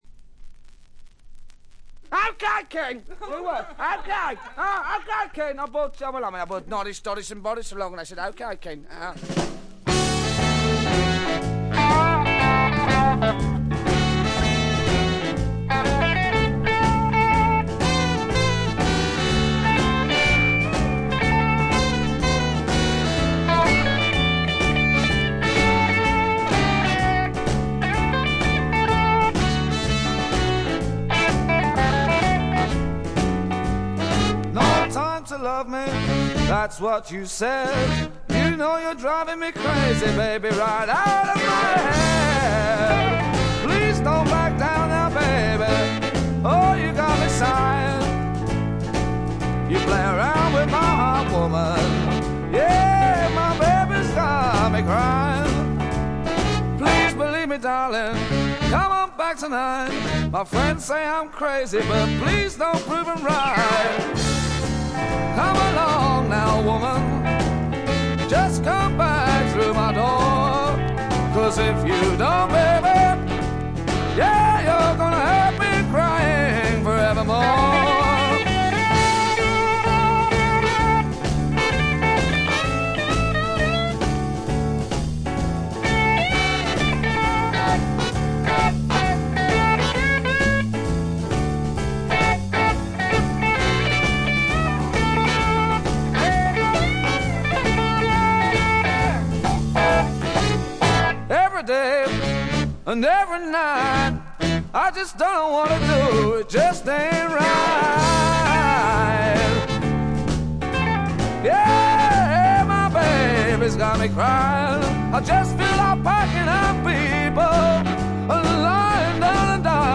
チリプチや散発的なプツ音はそこそこ出ますが鑑賞を妨げるようなものではありません。
英国ブルースロック名作中の名作。
初期モノラル・プレス。
試聴曲は現品からの取り込み音源です。（ステレオ針での録音です）